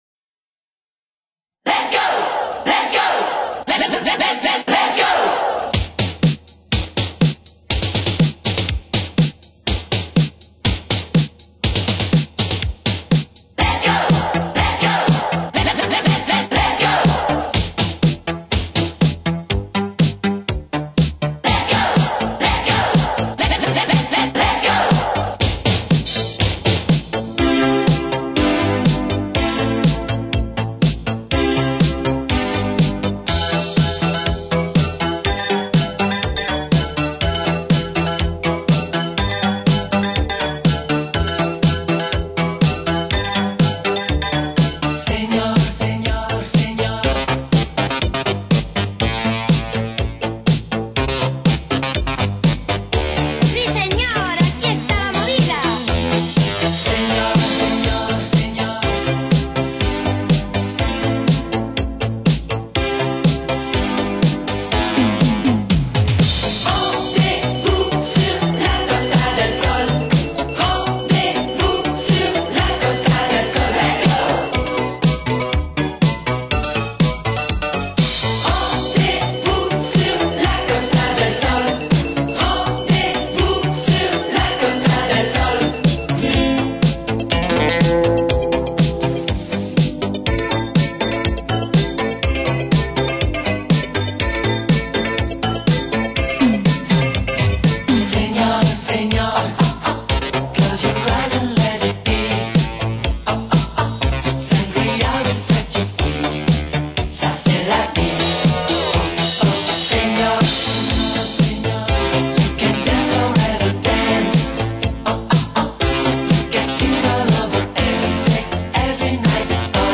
介绍： Italo disco